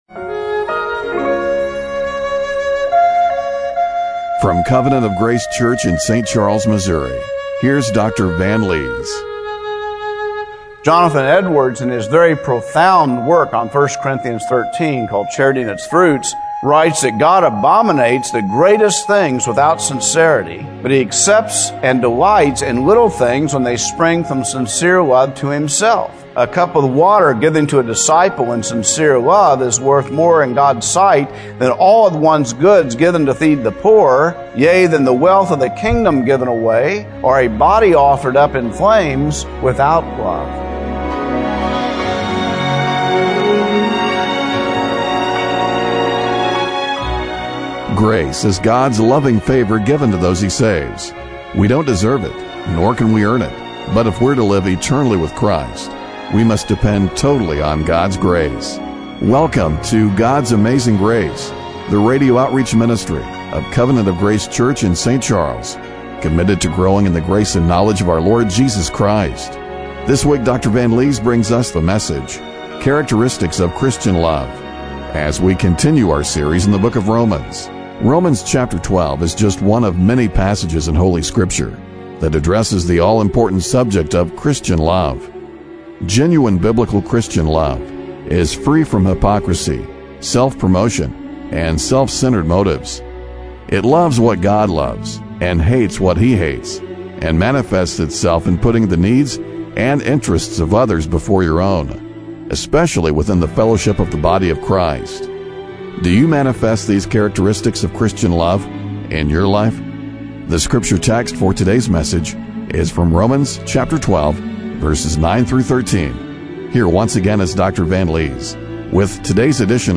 Romans 12:9-13 Service Type: Radio Broadcast Do you manifest the characteristics of Christian love in your life?